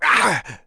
Zafir-Vox_Damage_01.wav